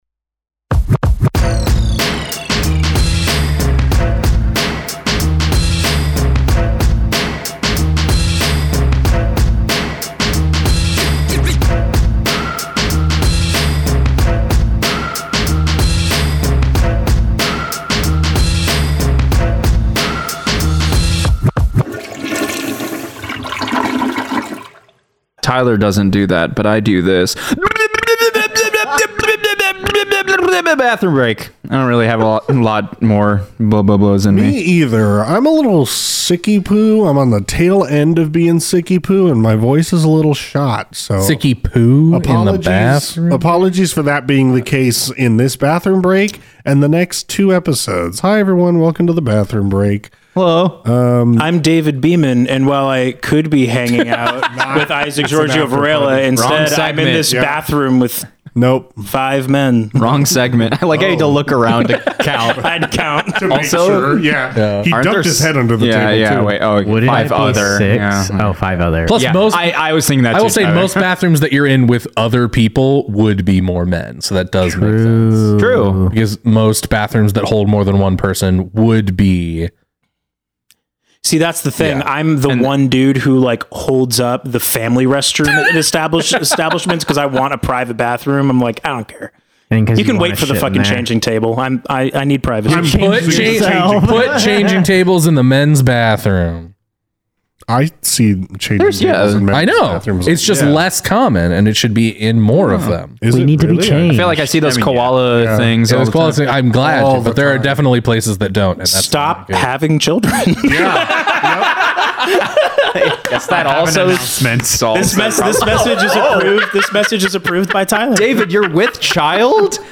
In this Bathroom Break, the cast discuss the events of episodes 29 and 30. Content Warnings: This episode contains strong language, fantasy violence.